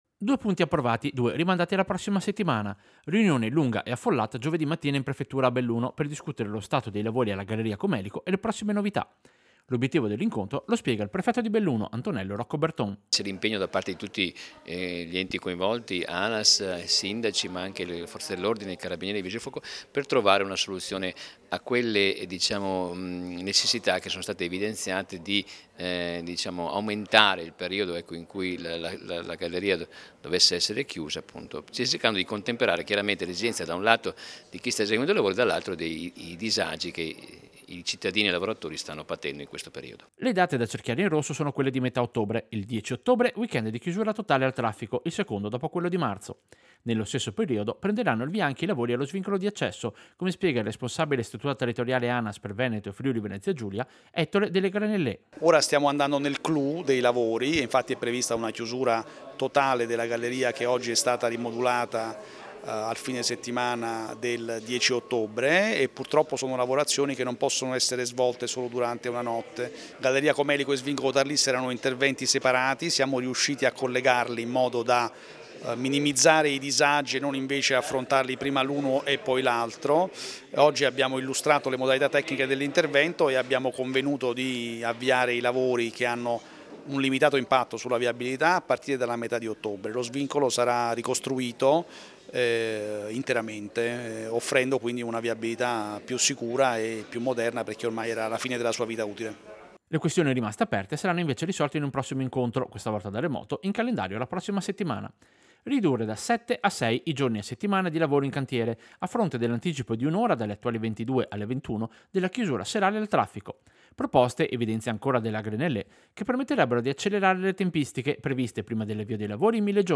Servizio-Aggiornamento-Prefettura-lavori-Galleria-Comelico.mp3